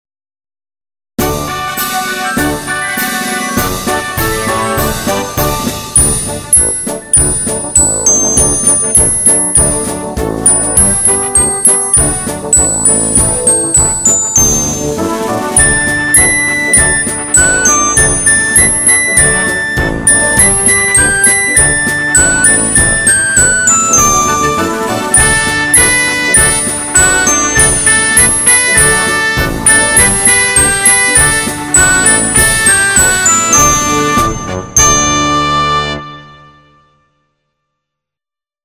Nauczycielska Orkiestra Flażoletowa